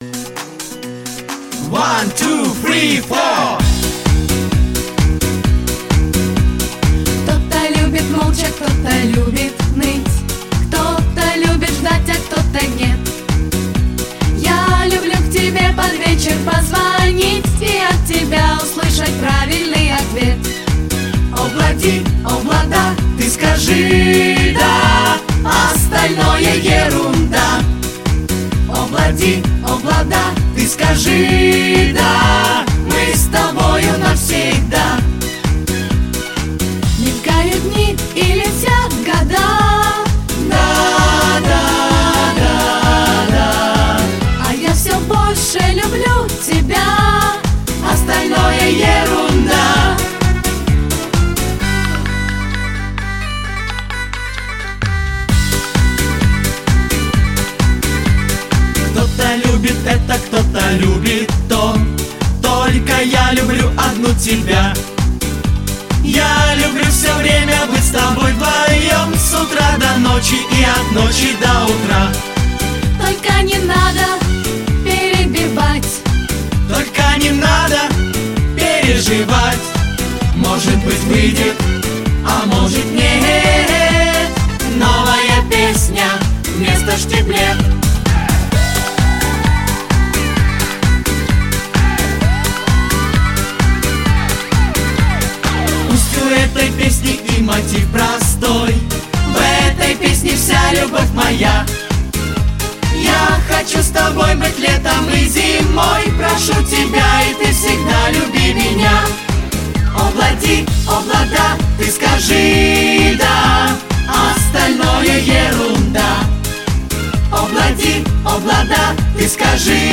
• Качество: Хорошее
• Жанр: Детские песни
🎶 Песни переделки 🥳 / Детские песни